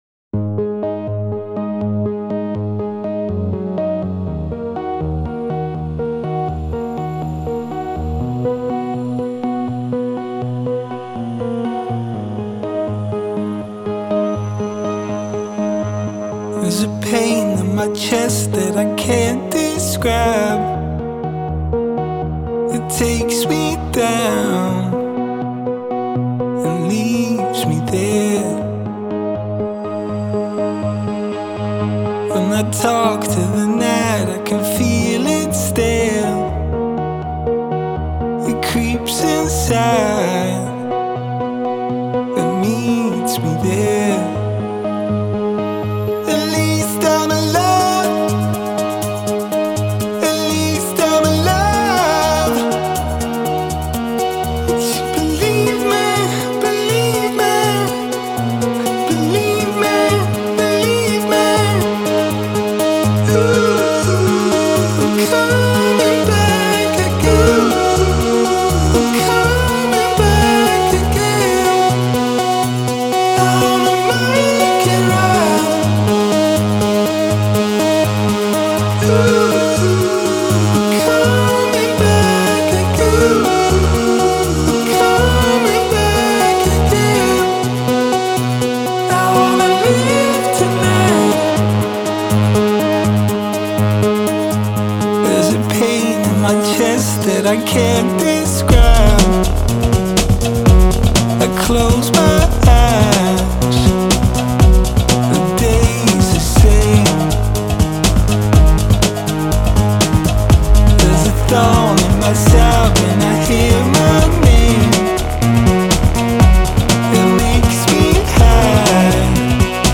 это трек в жанре электронного попа с элементами хауса